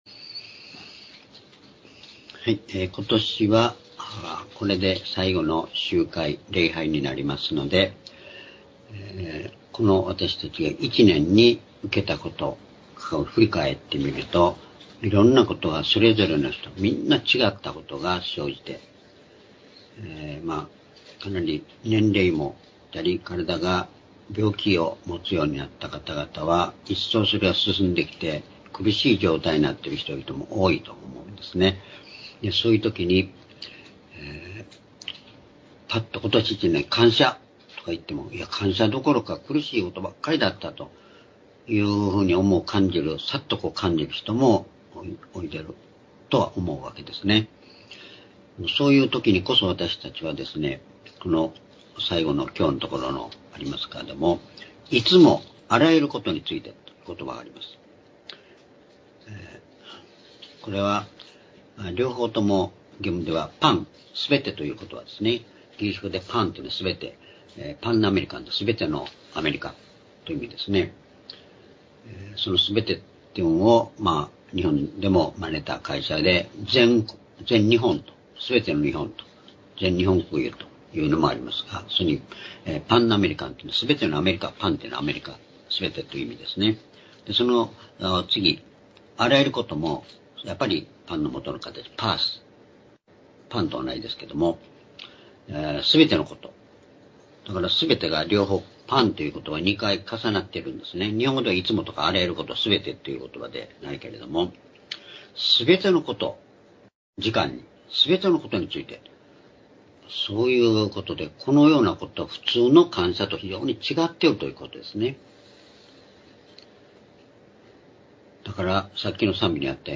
主日礼拝日時 2024年12月29日(主日礼拝) 聖書講話箇所 「いつも、あらゆることについて、感謝する」 エペソ書5章13～20節 ※視聴できない場合は をクリックしてください。